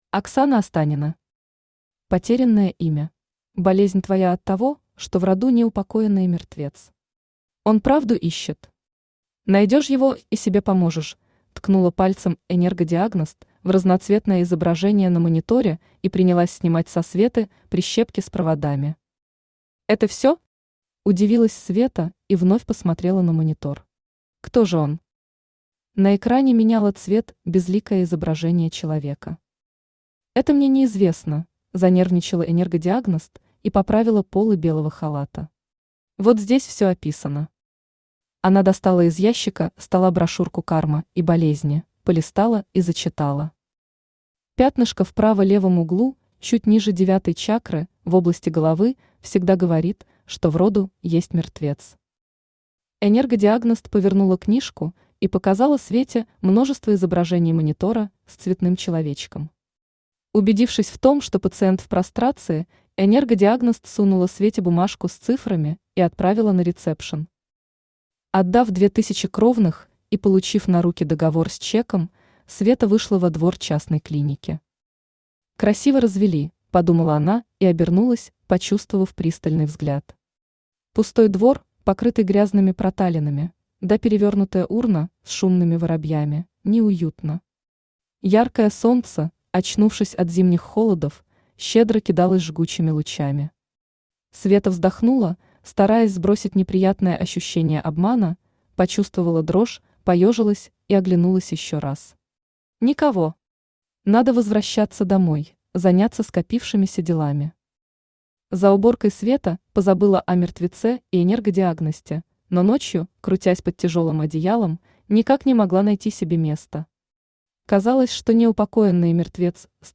Аудиокнига Потерянное имя | Библиотека аудиокниг